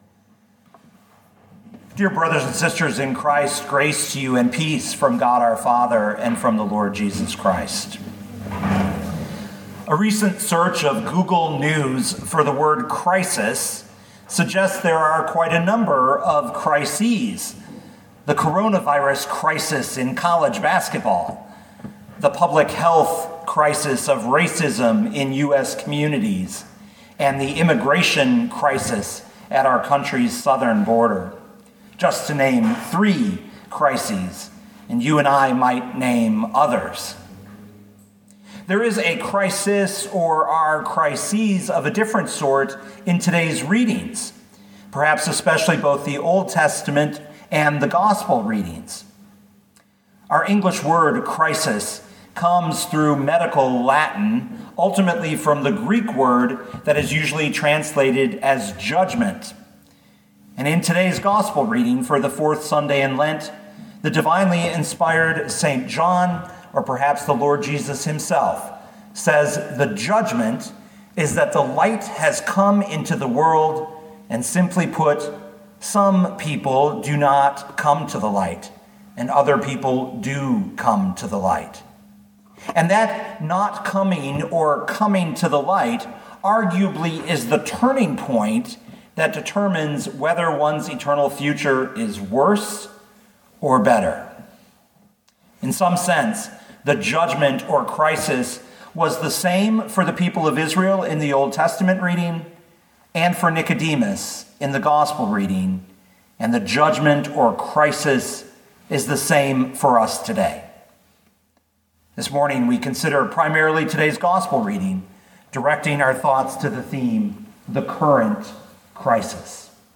2021 John 3:14-21 Listen to the sermon with the player below, or, download the audio.